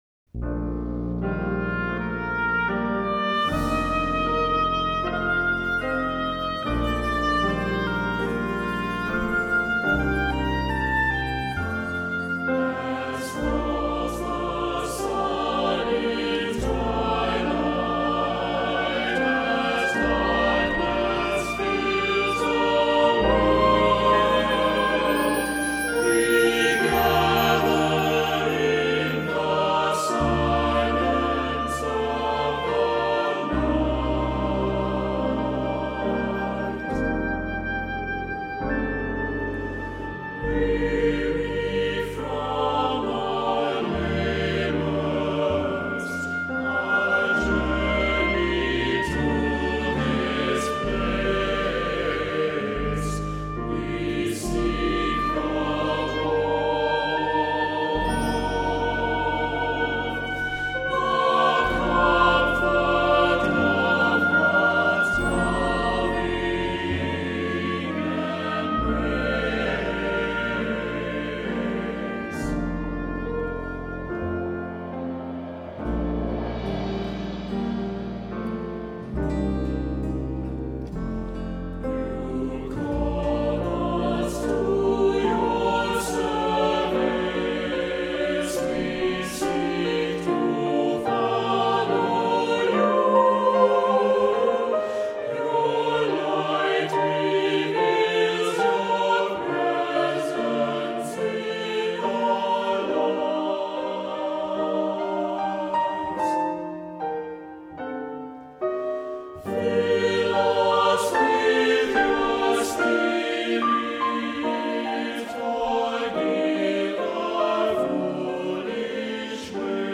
Accompaniment:      Keyboard, Oboe
Music Category:      Christian
Oboe part is optional.